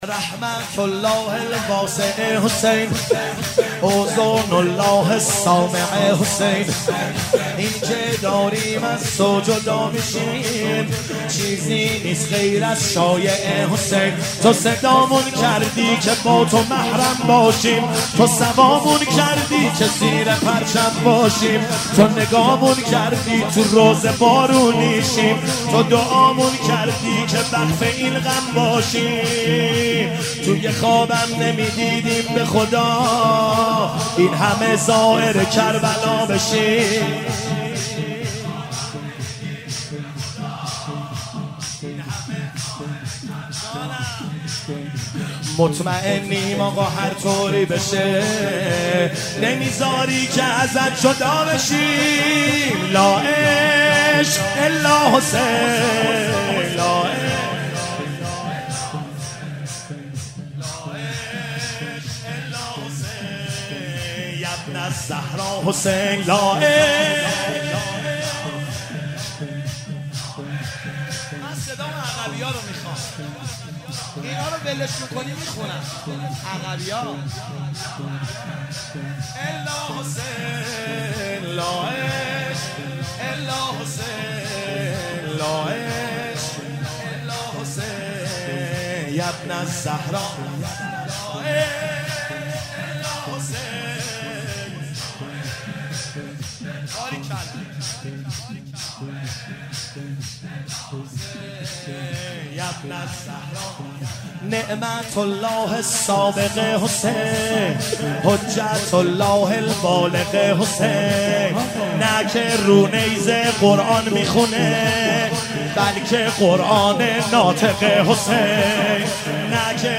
شب چهارم محرم 97 - شور - لا عشق الا حسین